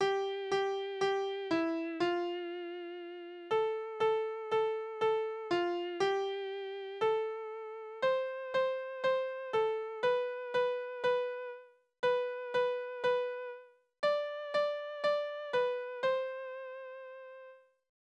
Tonart: C-Dur Taktart: 4/4 Tonumfang: Oktave Besetzung: instrumental